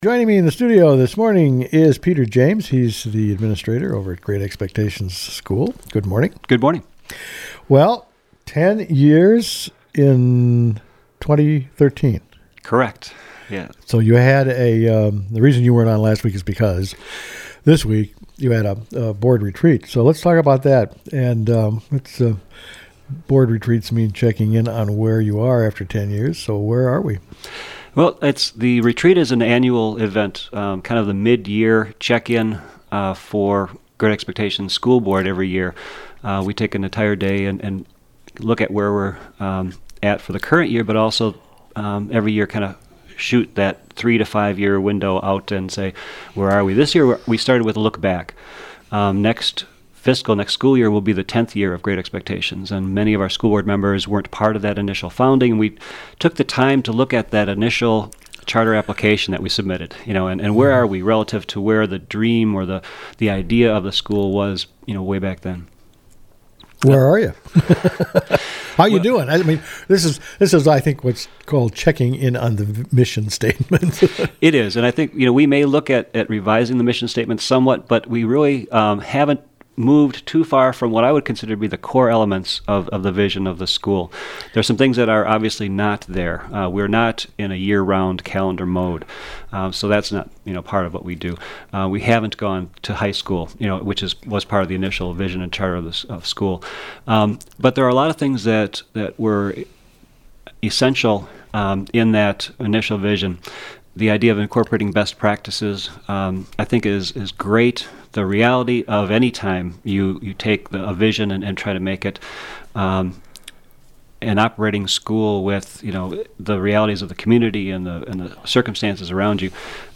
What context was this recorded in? The school board at Great Expectations held their annual retreat.